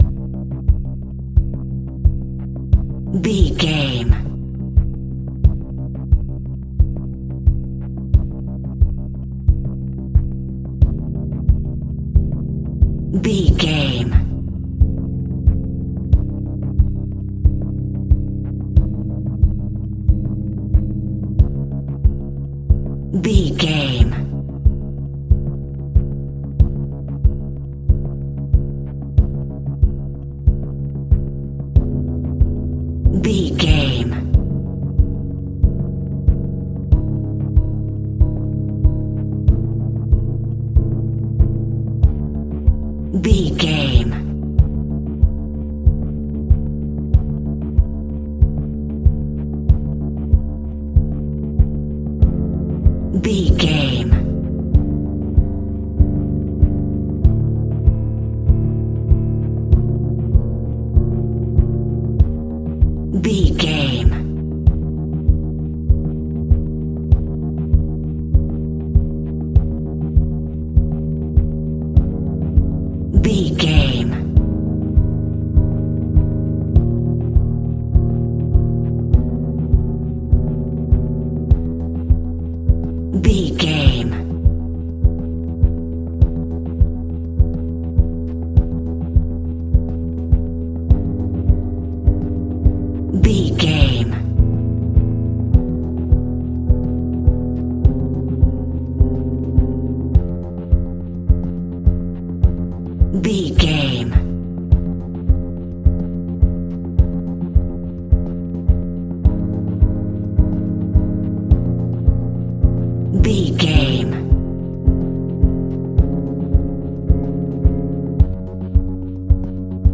Suspense that Rises.
In-crescendo
Thriller
Aeolian/Minor
Slow
scary
ominous
dark
haunting
eerie
synthesiser
drums
percussion
piano
mysterious